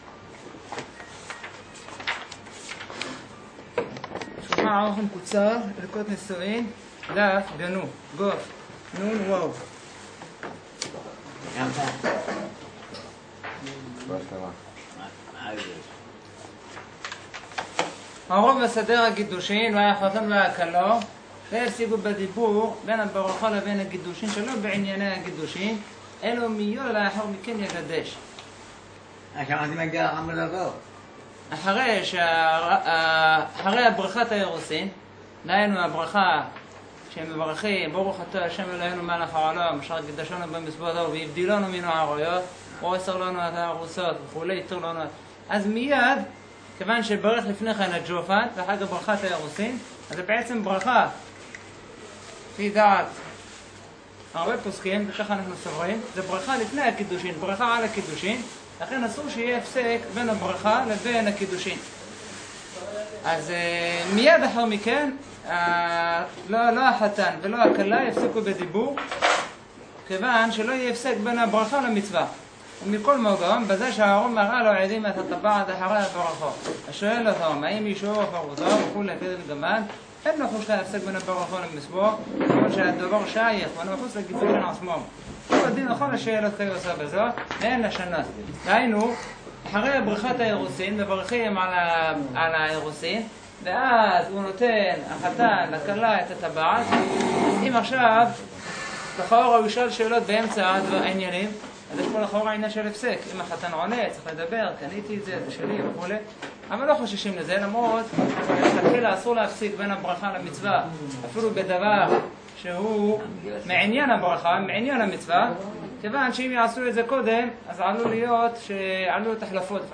נמסר במסגרת השיעור המקדים